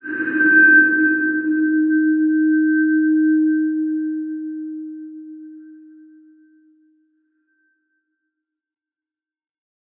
X_BasicBells-D#2-pp.wav